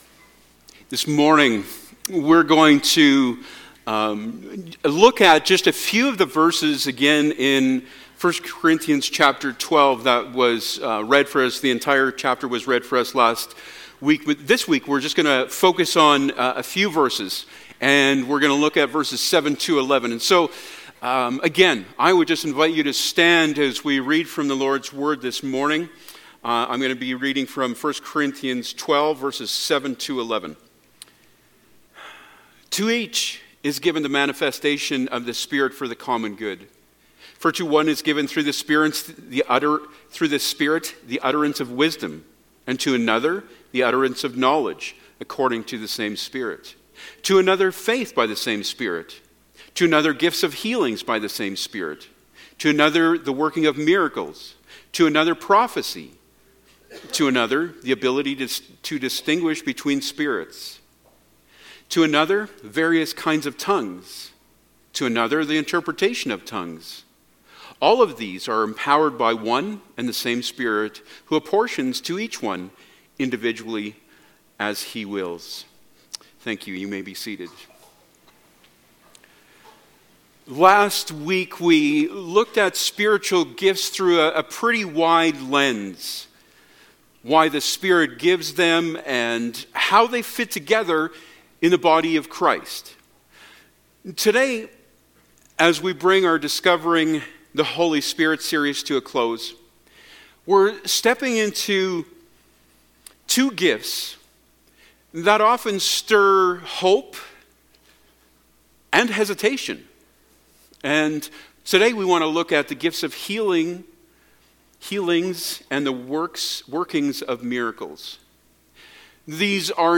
Service Type: Sunday Morning Topics: Holy Spirit